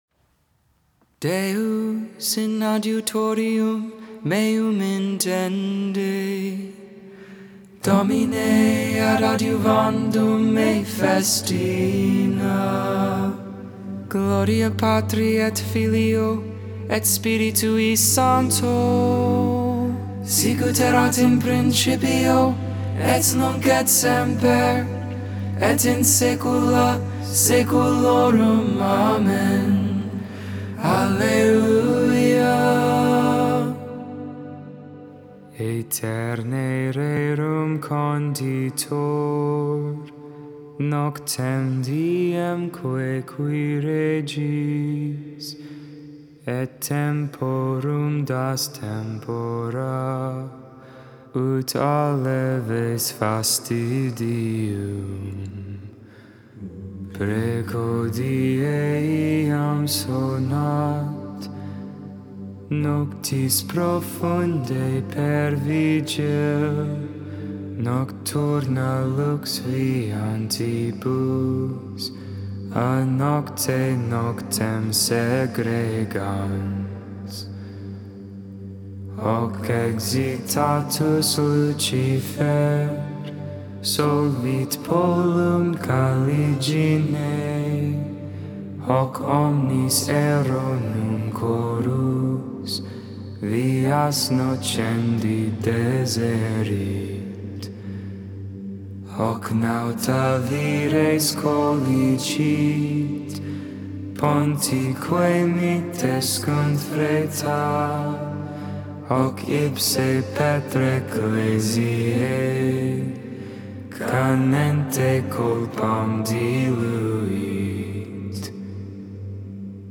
2.19.23 Lauds, Sunday Morning Prayer
Tone 8